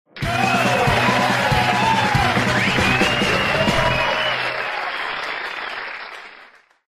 Звук аплодисментов под ритм победной музыки